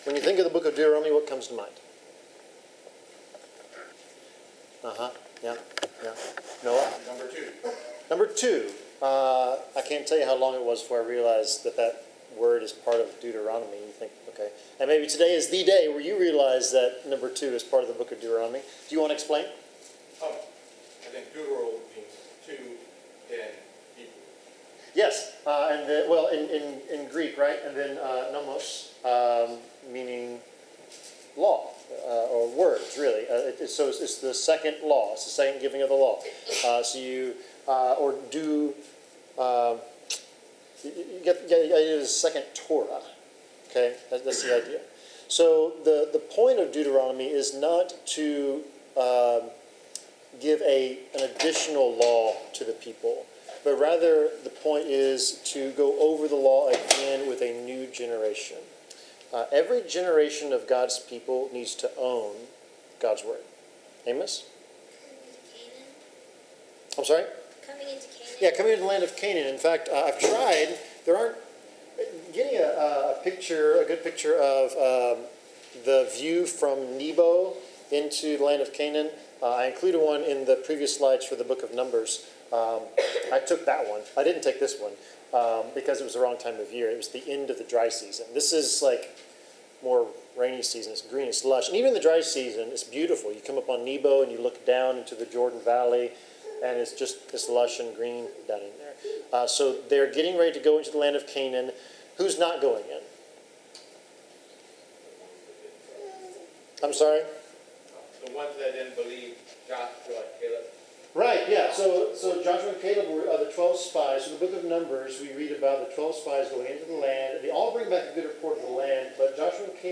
Bible class: Introducing the book of Deuteronomy
Service Type: Bible Class